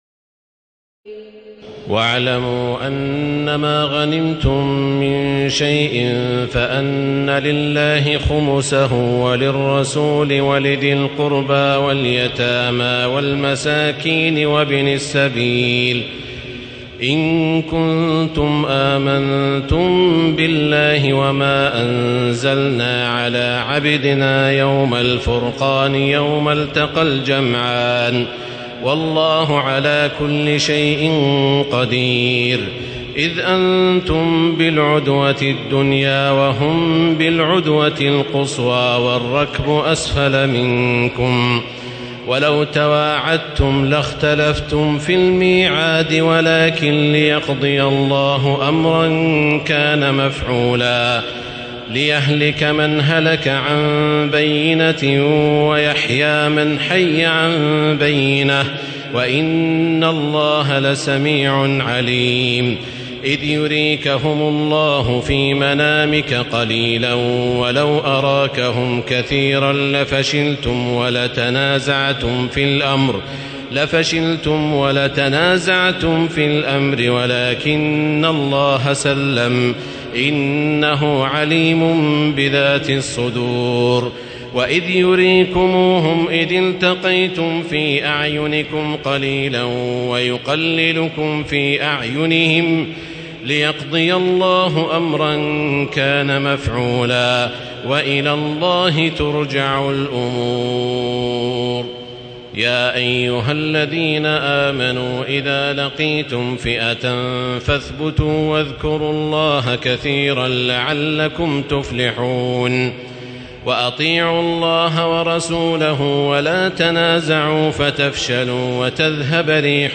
تراويح الليلة التاسعة رمضان 1437هـ من سورتي الأنفال (41-75) و التوبة (1-33) Taraweeh 9 st night Ramadan 1437H from Surah Al-Anfal and At-Tawba > تراويح الحرم المكي عام 1437 🕋 > التراويح - تلاوات الحرمين